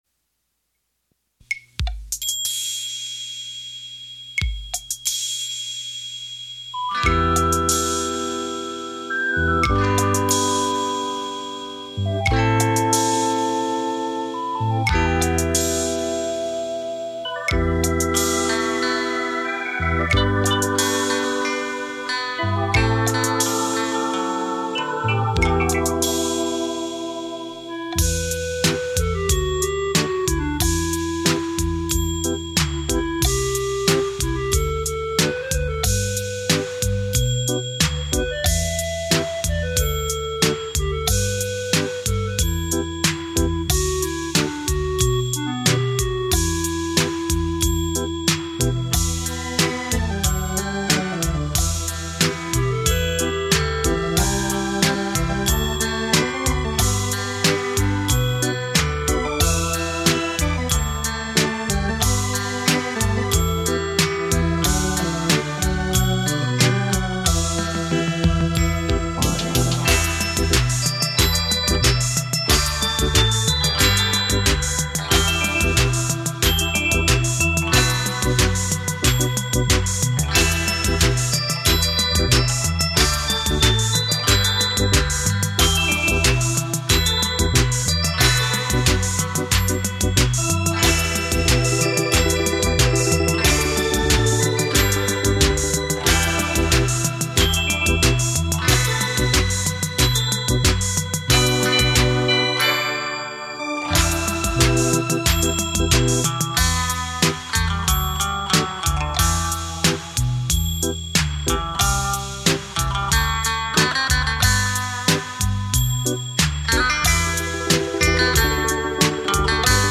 悦耳动容的丝弦本色
富有灵气的音符
唯美而浪漫的曲调
演绎流行的现代音乐